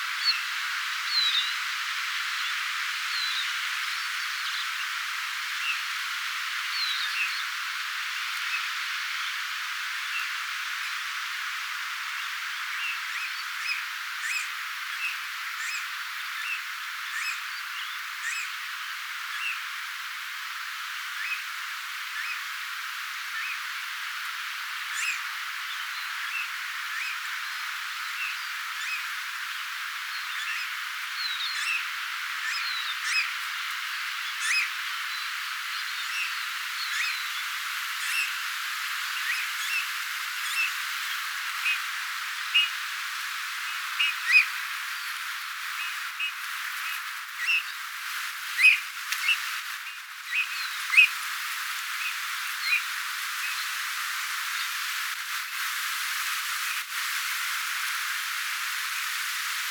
punatulkkunuorten ääntelyä
ilmeisia_punatulkkunuoria_kuin_yrittaisivat_matkia_niita_vihervarpusten_tulii-aania_ehka.mp3